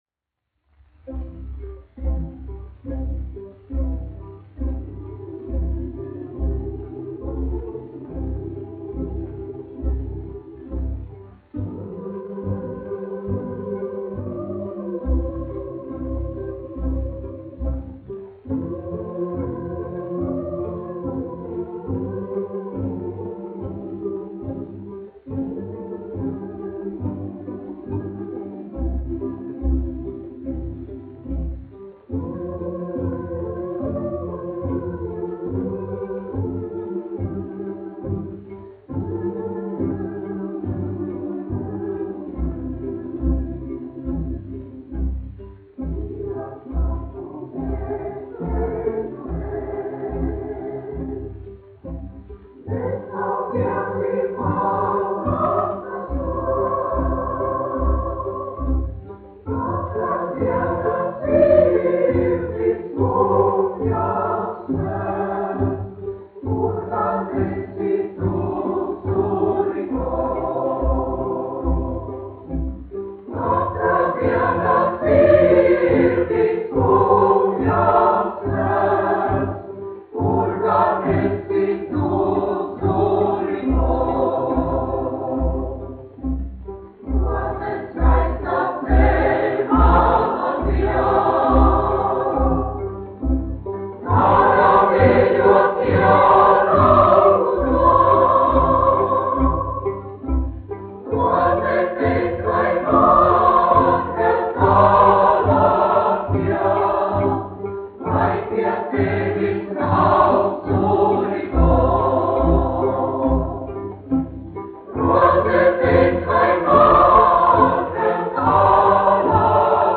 Reitera koris, izpildītājs
1 skpl. : analogs, 78 apgr/min, mono ; 25 cm
Gruzīnu tautasdziesmas
Kori (jauktie) ar orķestri